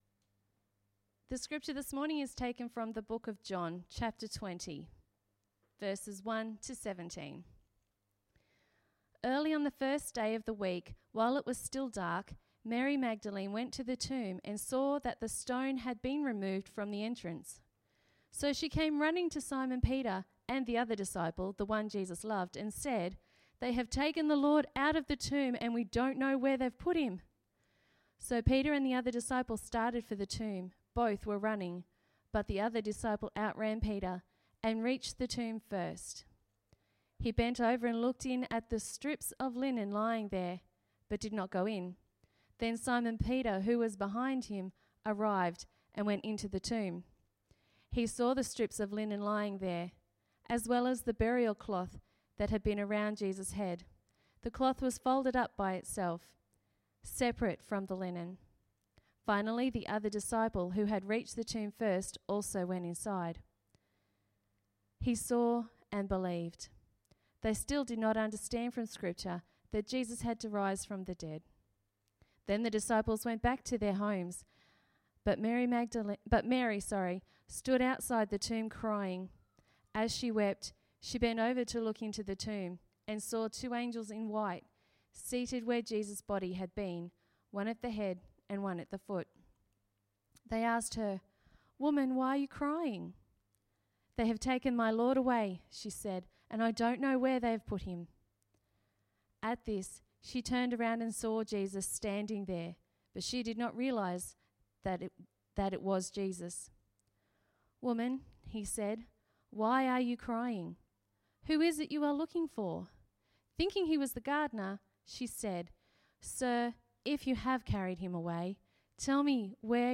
Sermon Easter Sunday